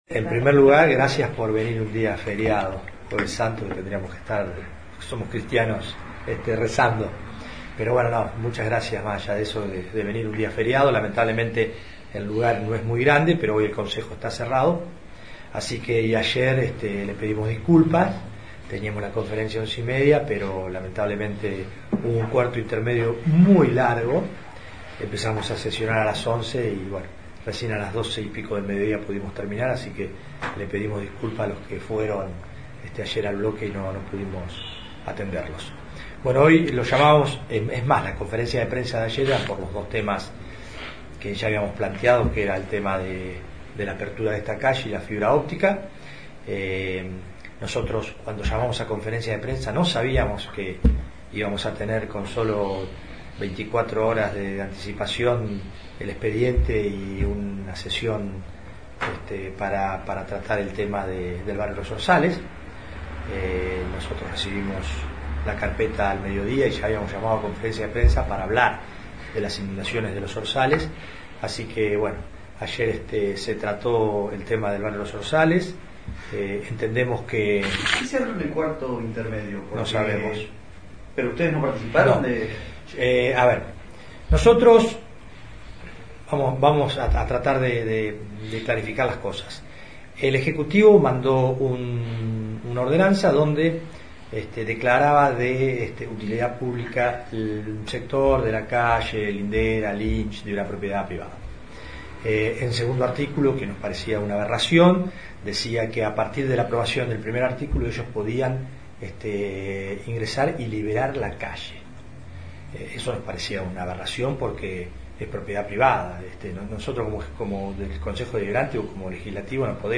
Conferencia de Prensa Bloque de Concejales Juntos - UCR CC